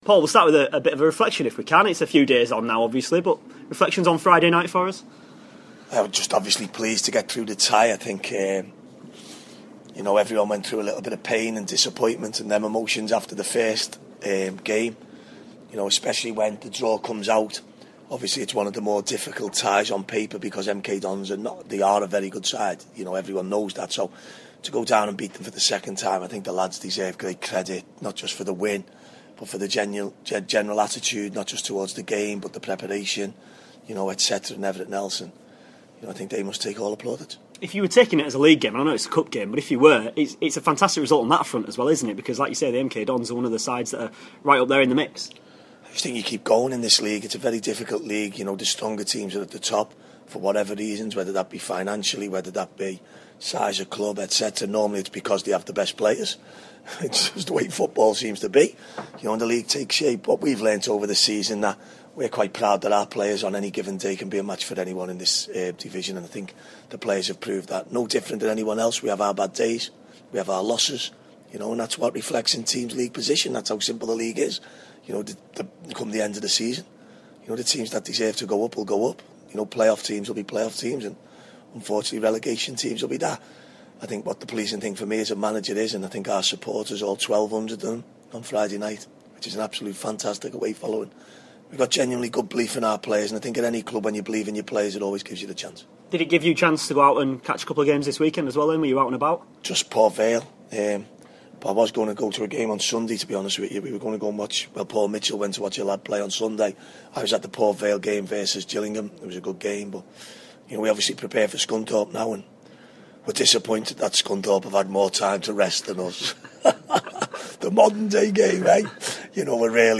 INTERVIEW: Chesterfield manager Paul Cook ahead of the Spireites FA Cup Third Round fixture with Scunthorpe